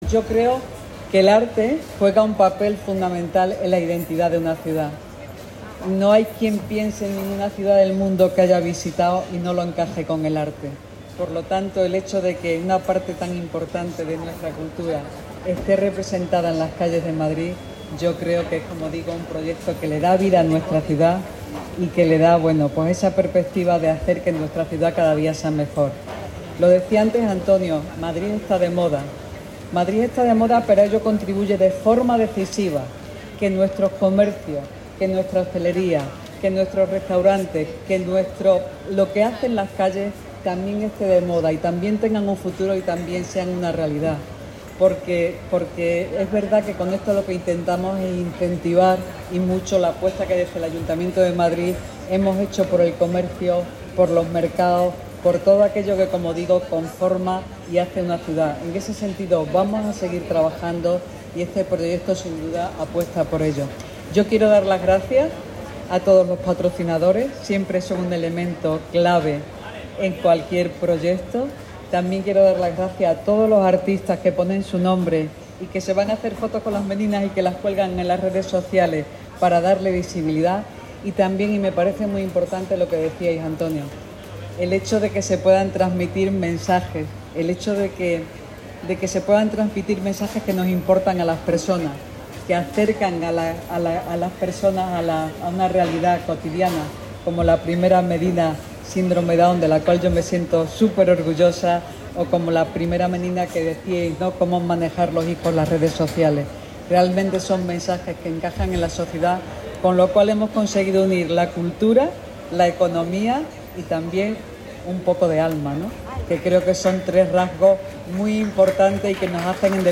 Nueva ventana:La delegada de Economía, Innovación y Hacienda, Engracia Hidalgo